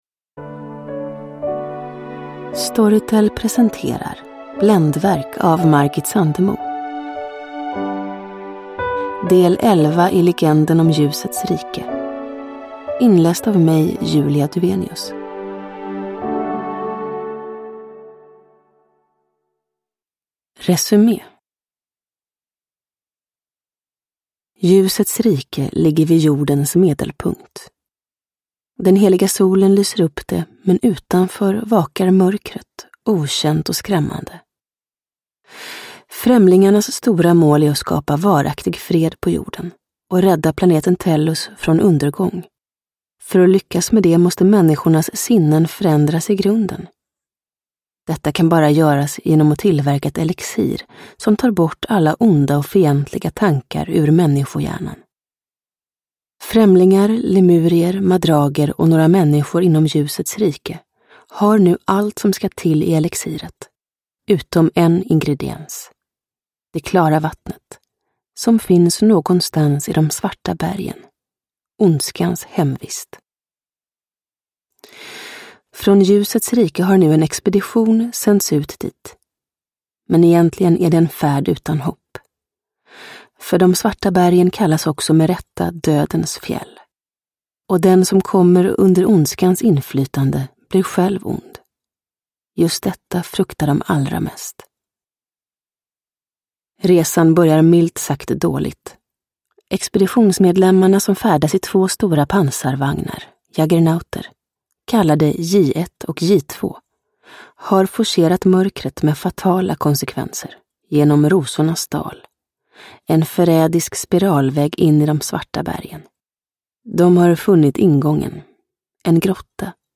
Bländverk – Ljudbok – Laddas ner